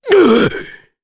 Gau-Vox_Damage_jp_01.wav